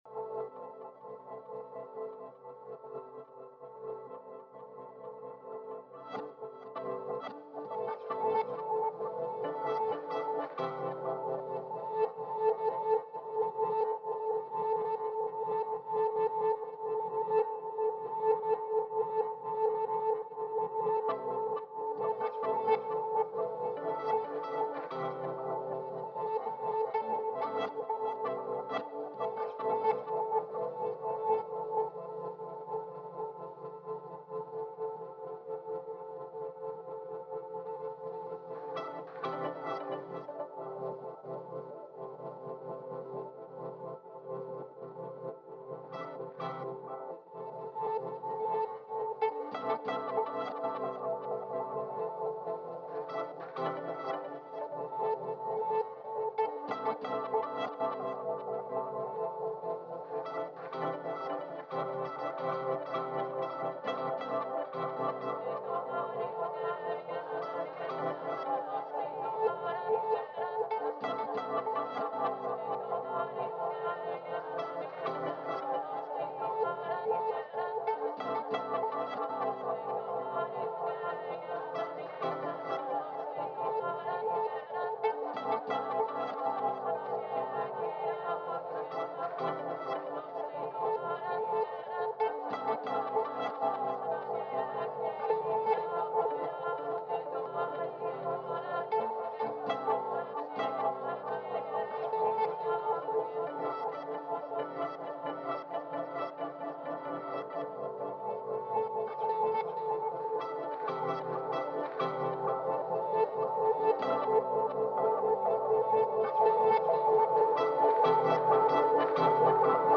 Drones, repeated patterns, pagan rhythms and world grooves.